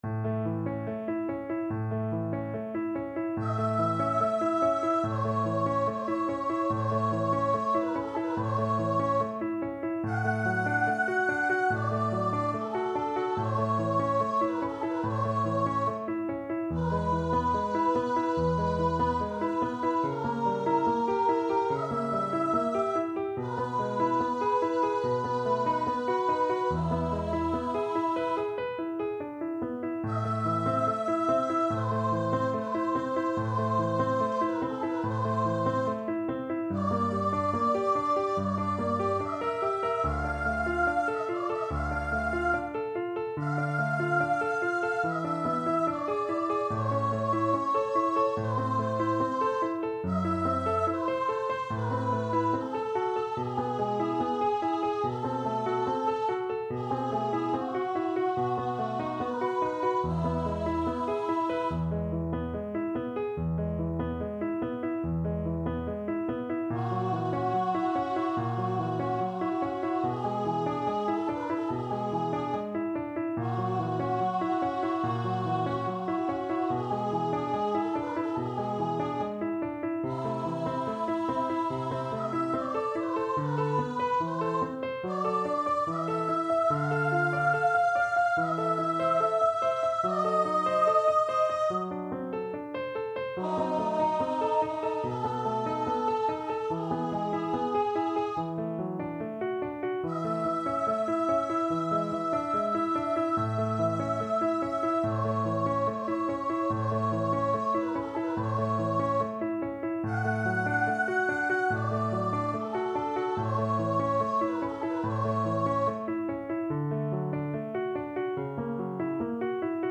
A major (Sounding Pitch) (View more A major Music for Vocal Duet )
Andante =72
Classical (View more Classical Vocal Duet Music)